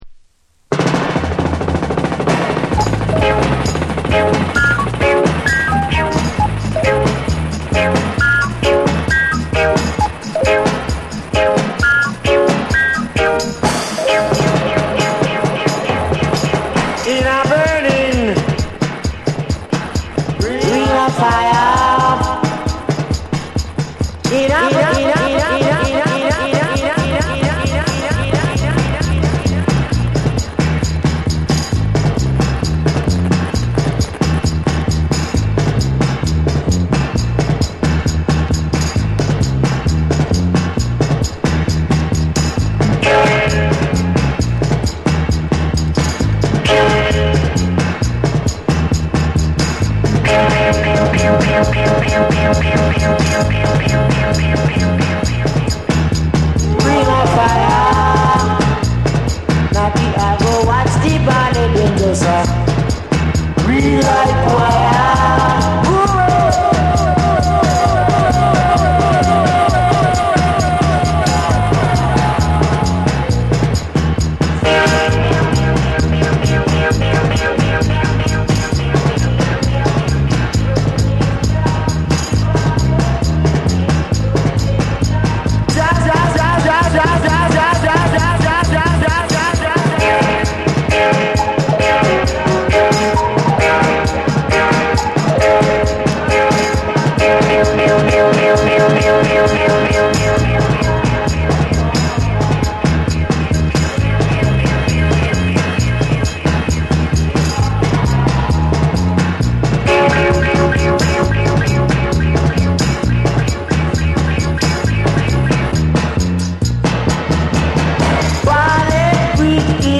重厚なベースと鋭いカッティング、ダイナミックなミキシングが際立つ70年代ミキシング・ダブの真髄を収録。
REGGAE & DUB